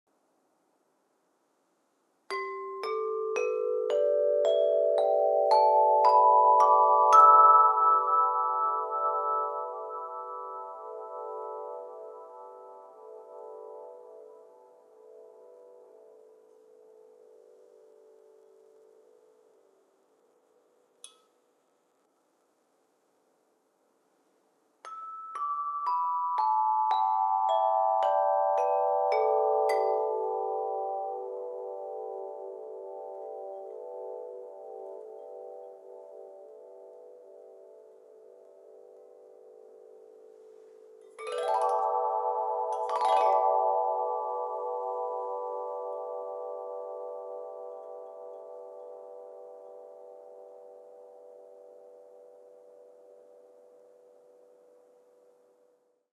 Meinl Sonic Energy Meditation Flow Chime 35"/88 cm - 440 Hz/10 Notes/G Major - Bronze (MFC10GMAB)
Meinl Sonic Energy Meditation Flow Chimes are high-quality instruments that are perfect for meditation, relaxation, and sound therapy.